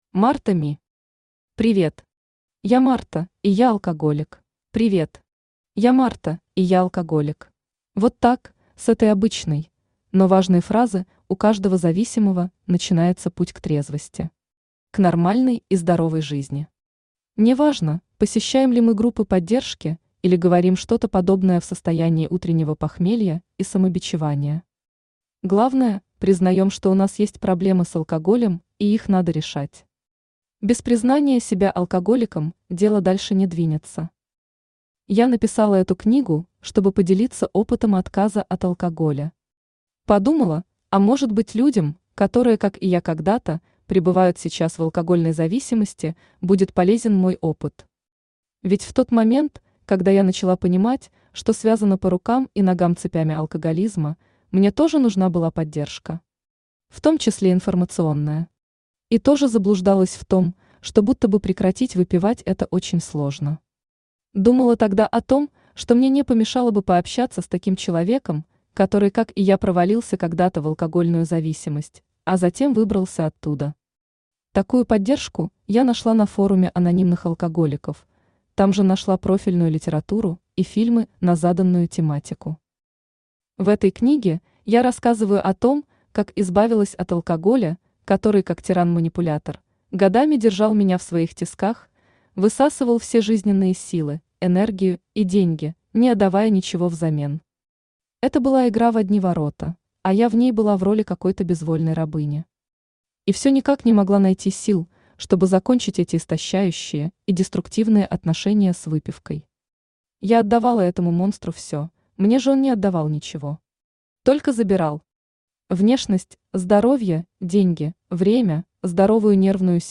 Аудиокнига Привет. Я Марта, и я алкоголик | Библиотека аудиокниг
Я Марта, и я алкоголик Автор Марта Ми Читает аудиокнигу Авточтец ЛитРес.